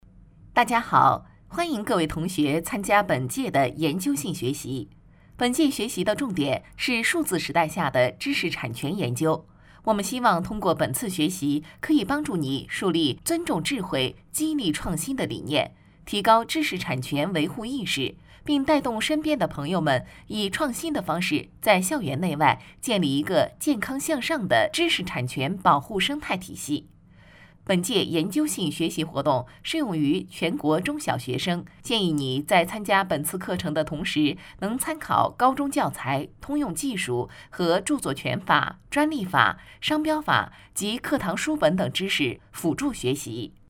女国94_多媒体_培训课件_知识产权.mp3